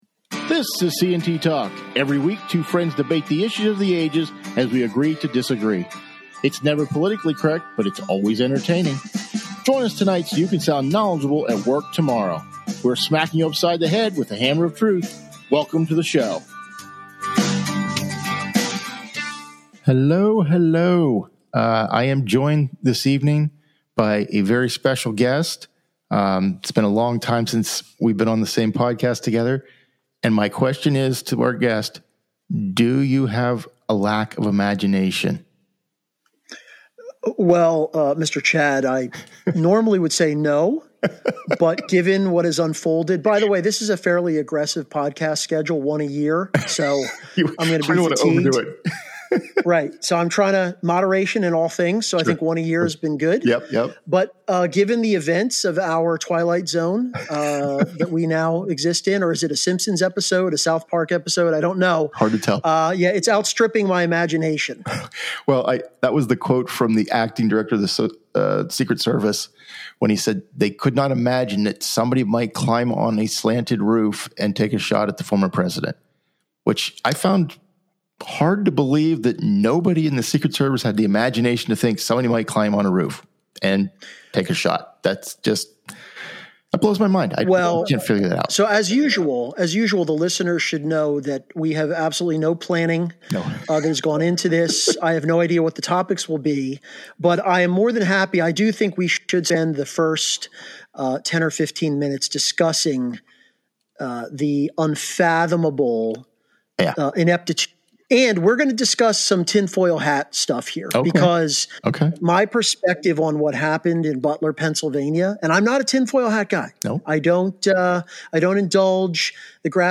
Two friends debate the issues of the ages.